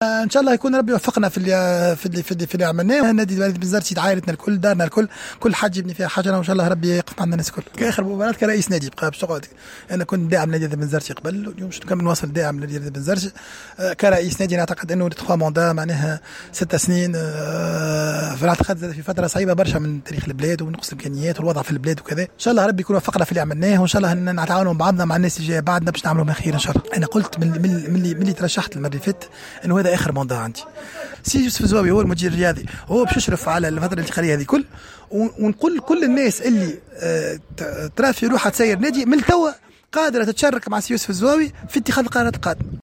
اكد رئيس النادي البنزرتي مهدي بن غربية في تصريح لجوهرة اف ام اثر نهاية مباراة الجولة الختامية التي جمعت فريقه بالنادي الافريقي في البطولة انها المباراة الاخيرة له كرئيسا للنادي .